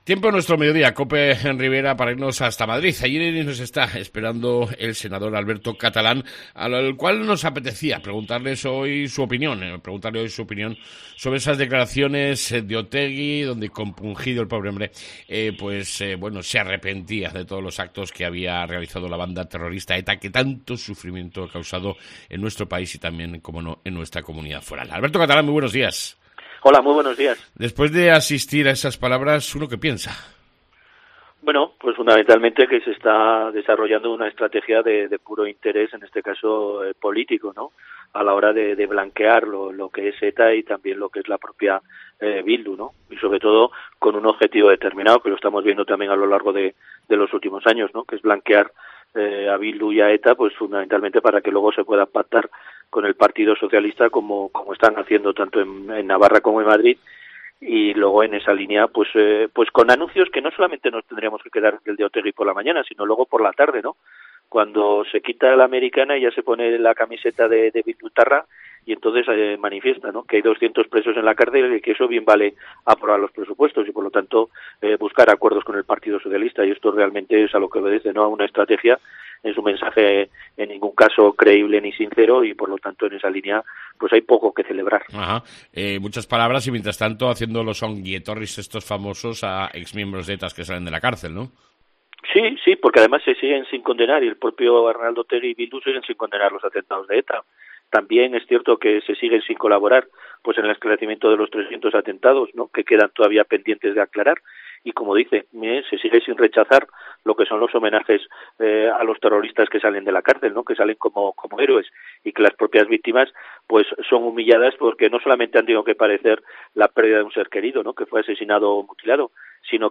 AUDIO: ENTREVISTA CON EL SENADOR ALBERTO CATALÁN TRAS LAS DECLARACIONES DE OTEGUI